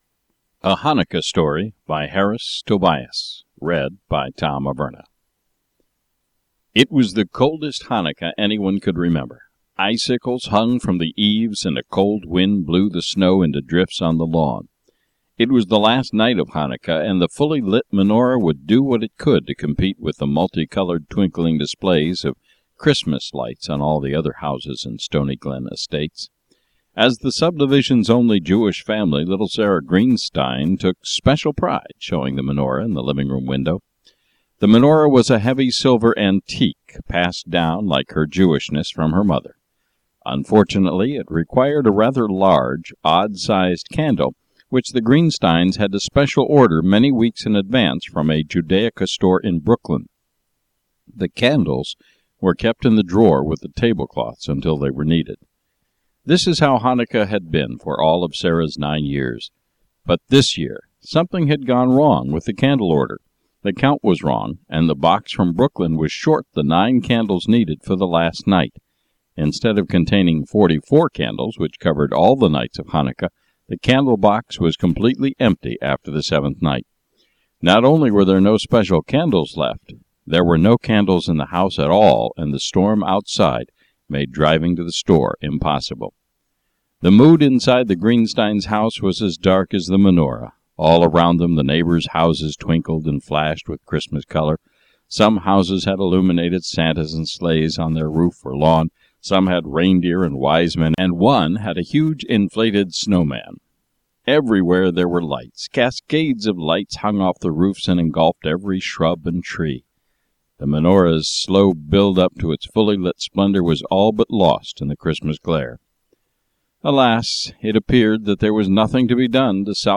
Reading A Chanukah Story